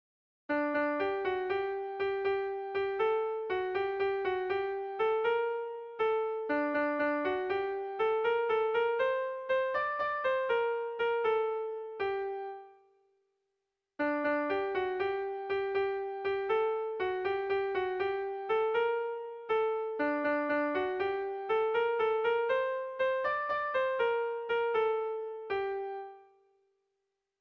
Bertso melodies - View details   To know more about this section
Irrizkoa
ABD